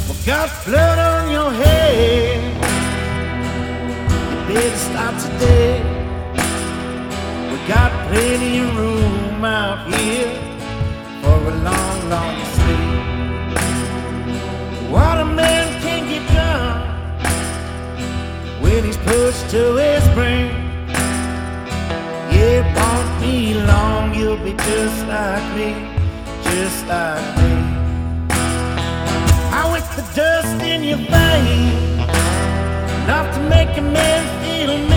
Жанр: Кантри
Blues, Country